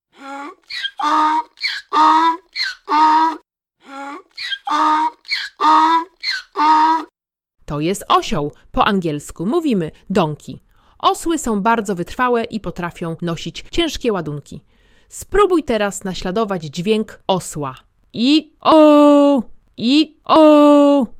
2⃣ Zwierzęta w gospodarstwie
• Odgłosy zwierząt.
osiol.mp3